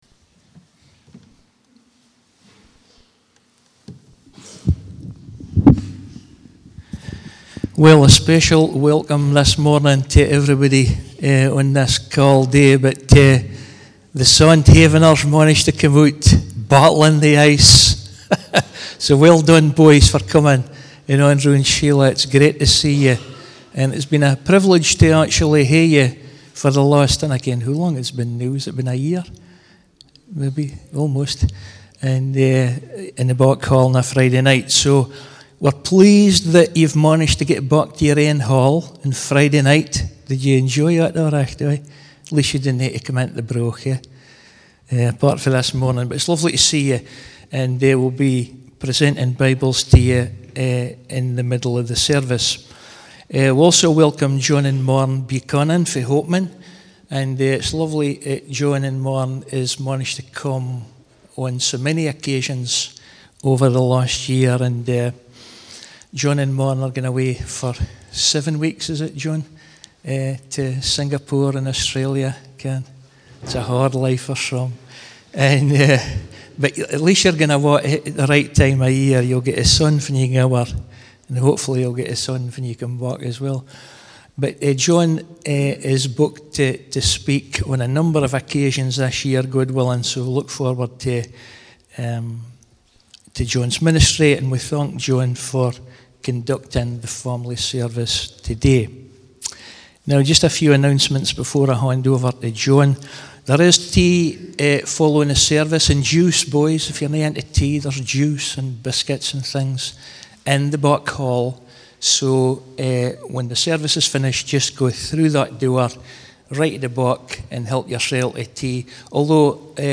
Family Service